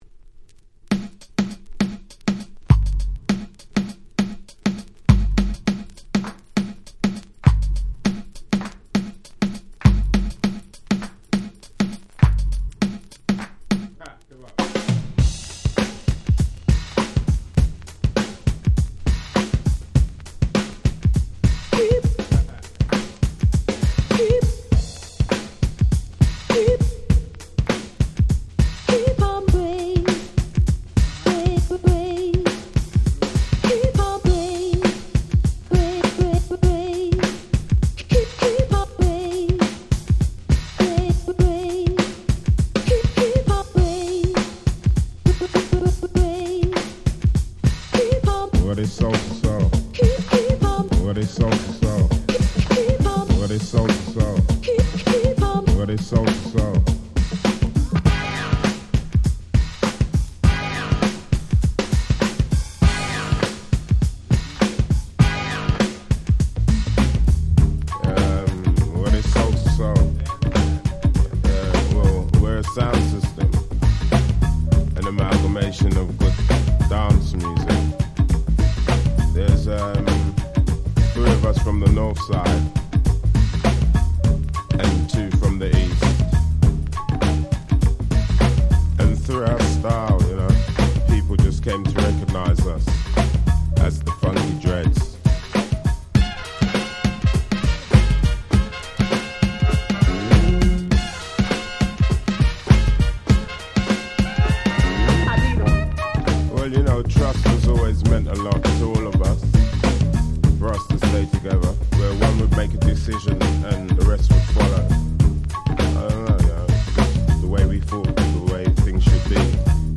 UK Original Press.
UK Soul/Ground Beat Classic !!